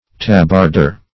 Search Result for " tabarder" : The Collaborative International Dictionary of English v.0.48: Tabarder \Tab"ard*er\, n. 1. One who wears a tabard.